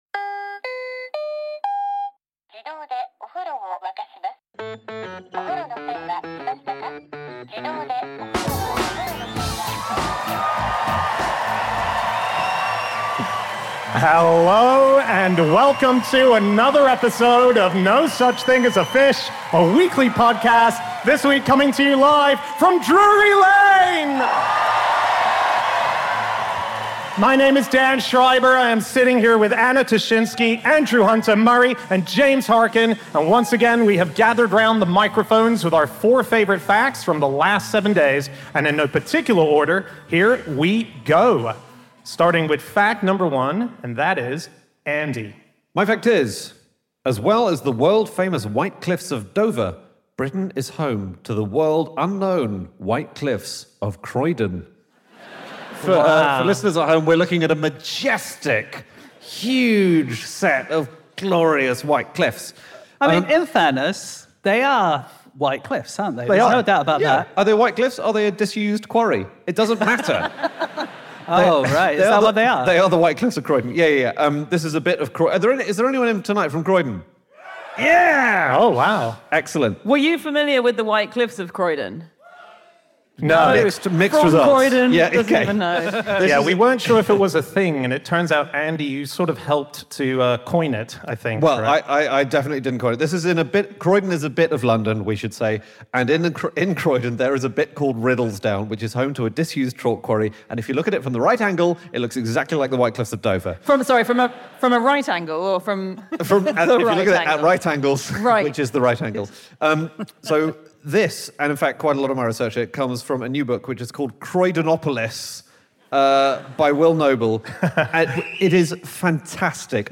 Live from the Theatre Royal, Drury Lane